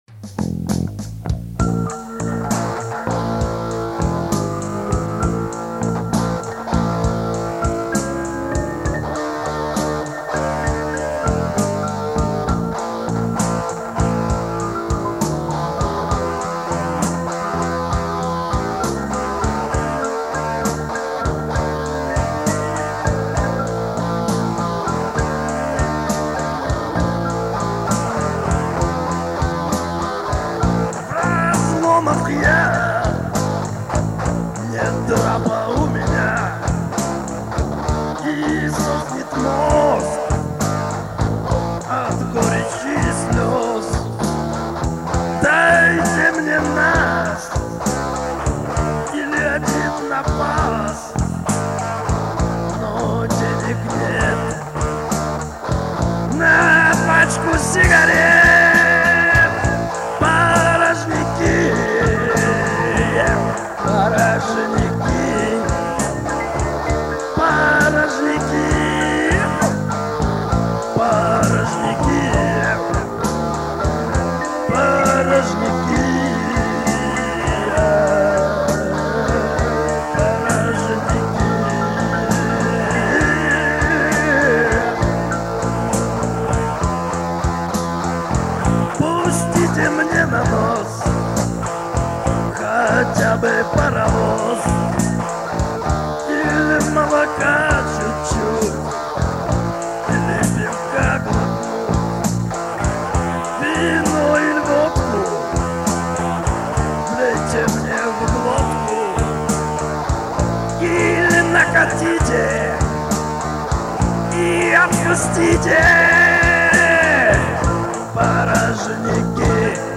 Русский рок Hard Rock